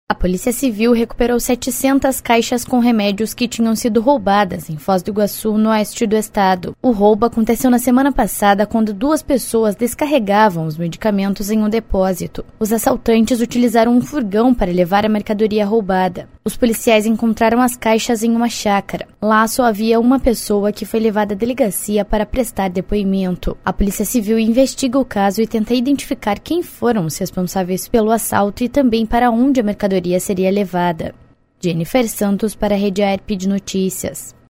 22.05 - BOLETIM SEM TRILHA - Polícia recupera medicamentos roubados em Foz do Iguaçu
22.05-BOLETIM-SEM-TRILHA-Polícia-recupera-medicamentos-roubados-em-Foz-do-Iguaçu.mp3